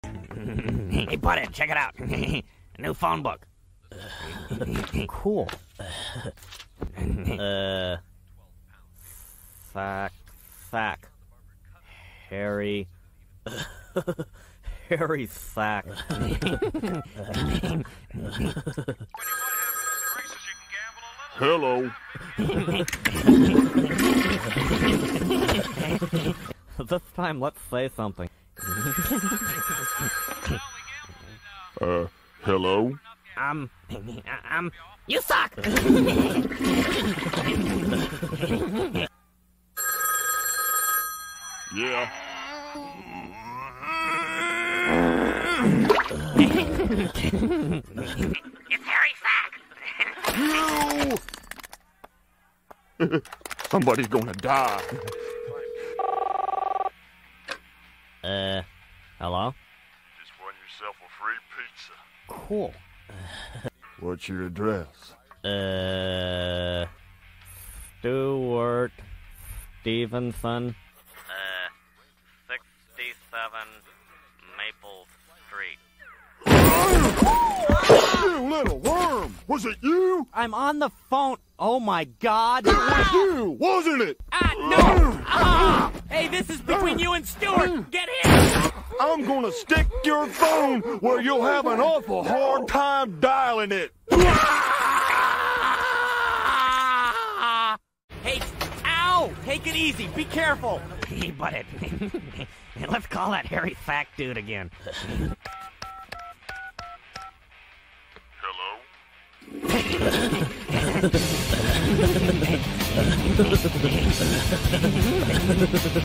Beavis and butthead - prank call